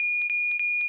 Oxygen Alert Sound.wav